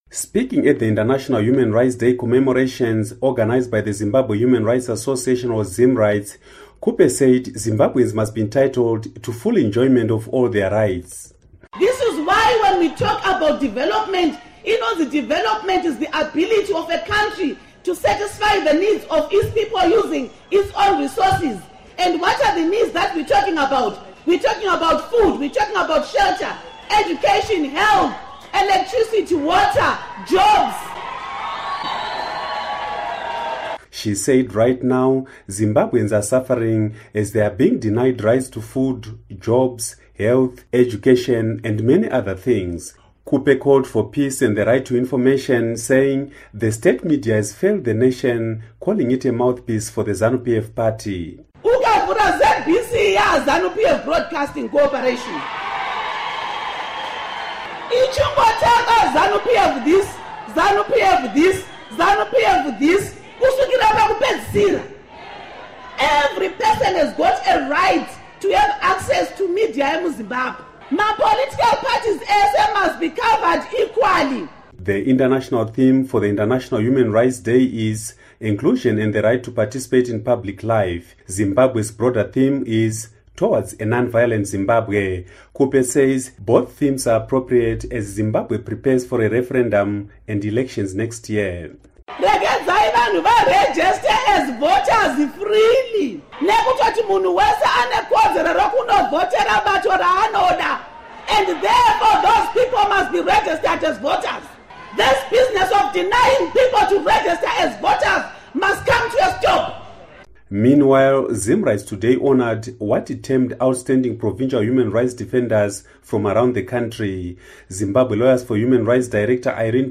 Speaking at the International Human Rights Day commemorations organised by the Zimbabwe Human Rights Association, Khupe said Zimbabweans must be entitled to full enjoyment of all their rights.
Report